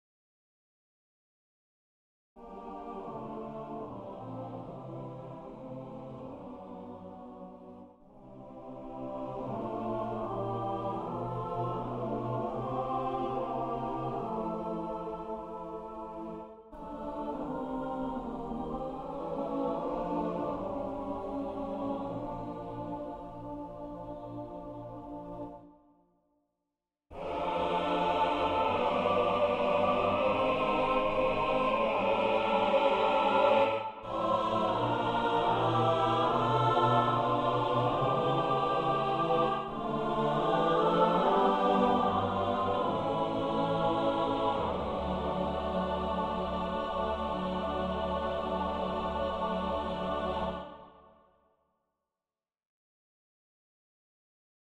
MuseScore mockup
Chorale harmonization.